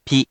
We have our computer friend, QUIZBO™, here to read each of the hiragana aloud to you.
#3.) Which hiragana do you hear? Hint: 【pi】
In romaji, 「ぴ」 is transliterated as 「pi」which sounds like the letter 「P」or 「pea」in more of an American accent.